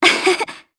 Yanne-Vox_Happy1_jp.wav